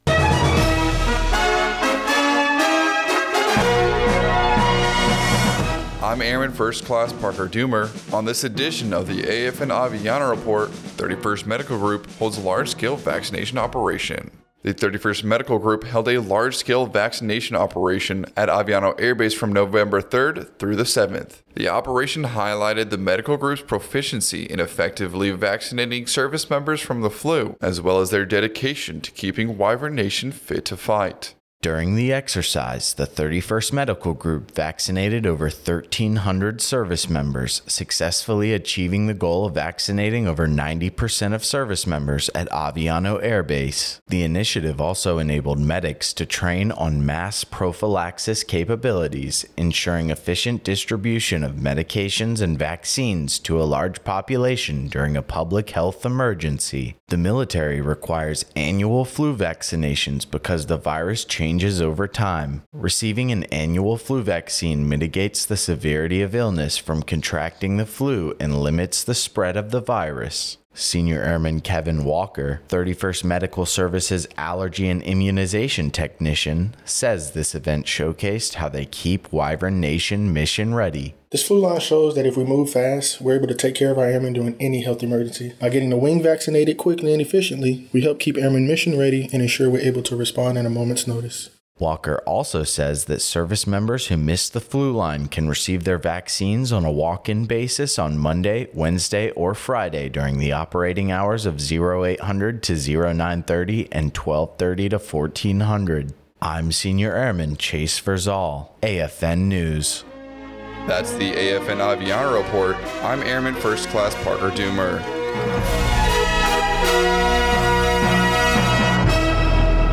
American Forces Network Aviano radio news reports on the large-scale vaccination operation held by the 31st Medical Group at Aviano Air Base, Italy, Nov. 3-7, 2025. The operation showcased the medical group’s ability to effectively vaccinate service members from the flu virus, as well as their dedication to keeping Wyvern nation fit to fight.